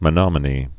(mə-nŏmə-nē)